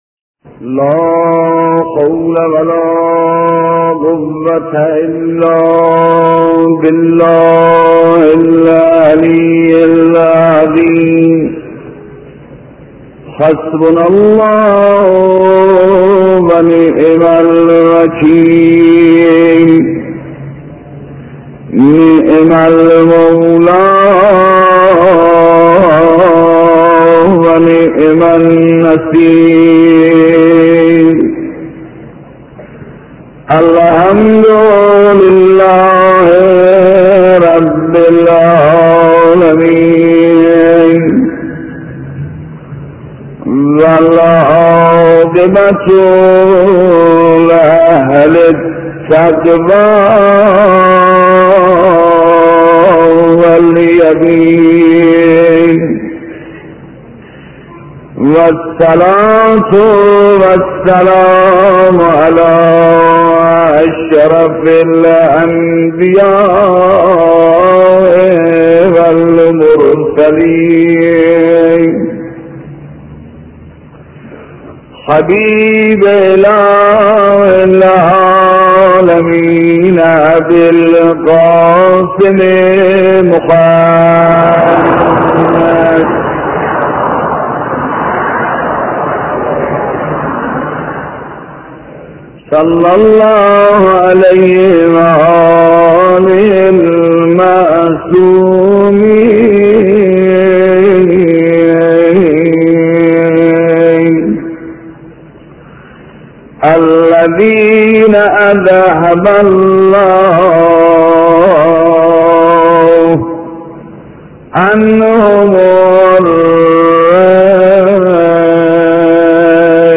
روضه درباره امام زمان (عج) و فرج ایشان 2